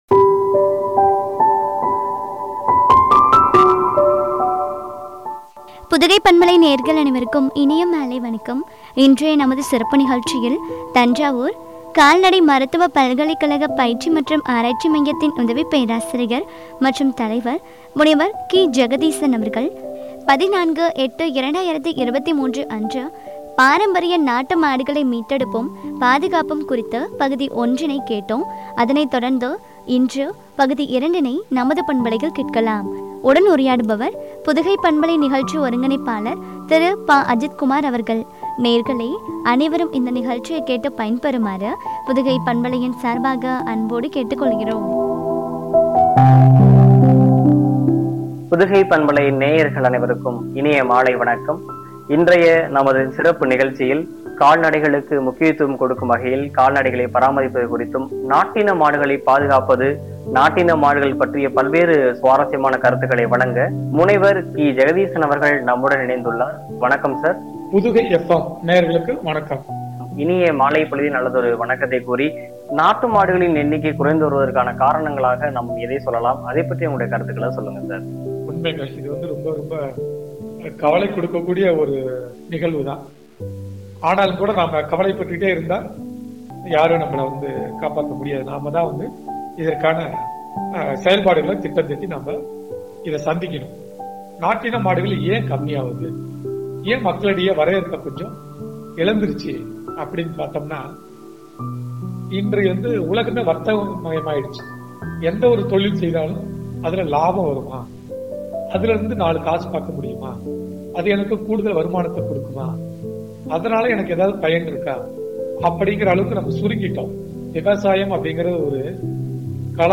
(பகுதி 2) குறித்து வழங்கிய உரையாடல்.